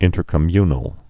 (ĭntər-kə-mynəl)